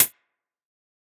Index of /musicradar/ultimate-hihat-samples/Hits/ElectroHat B
UHH_ElectroHatB_Hit-01.wav